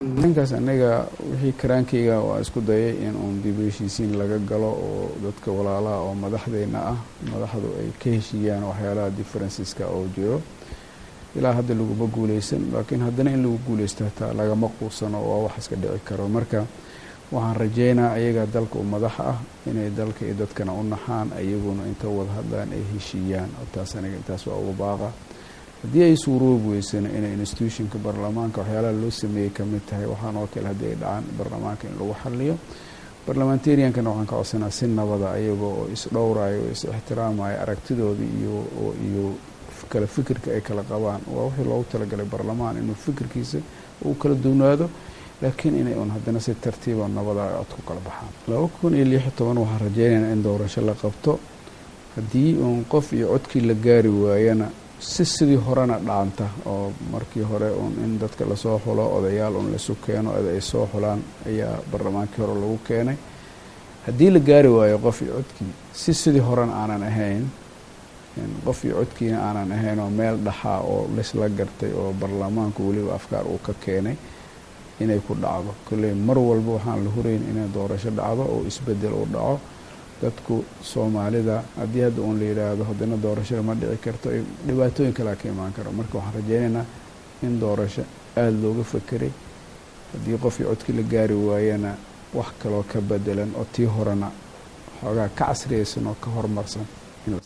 DHAGEYSO: Shariif Xasan oo ka hadlaya Khilaafka Madaxda